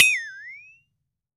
FLEXATONE  1.WAV